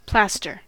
Ääntäminen
Vaihtoehtoiset kirjoitusmuodot (vanhentunut) plaister (vanhentunut) plastre Synonyymit band-aid plaster cast plaster of Paris Ääntäminen US : IPA : /ˈplæs.tɚ/ Northern England: IPA : /ˈplæs.tɚ/ RP : IPA : /ˈplɑːs.tə/